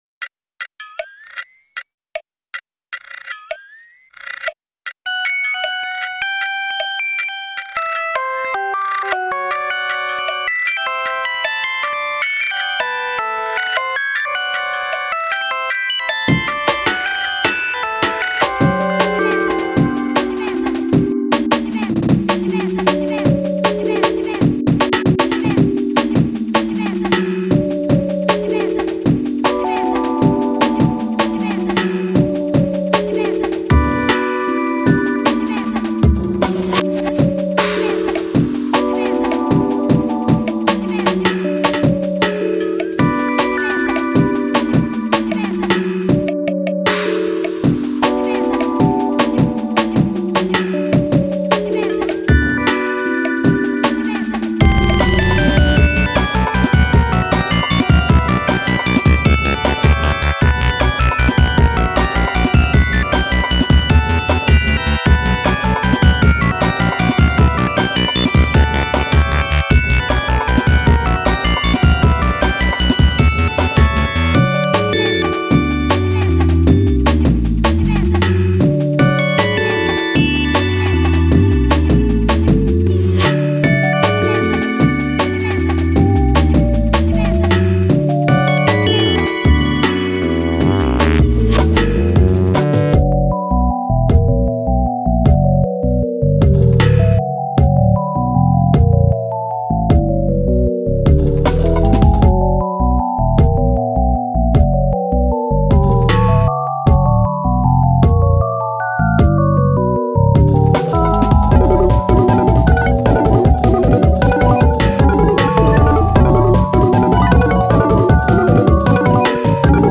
snowflakes on my windshield: amen waltz